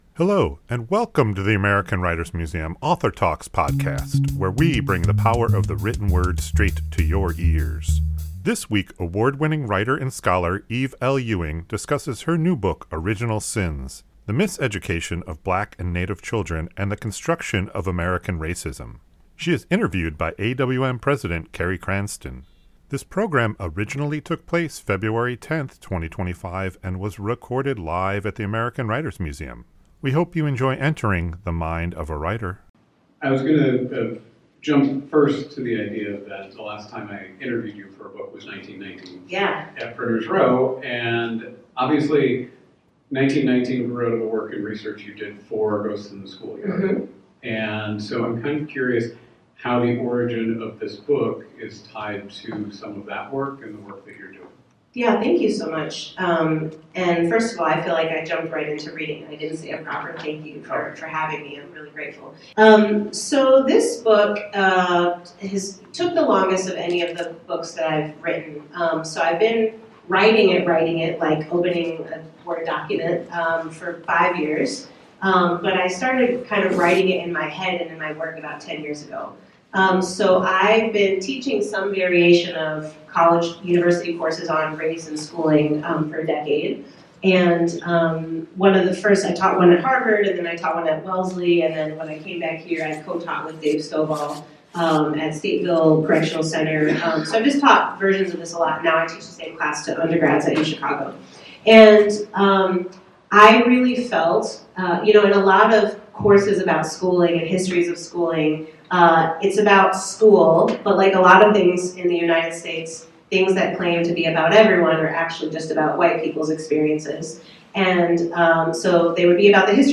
This conversation originally took place February 10, 2025 and was recorded live at the American Writers Museum.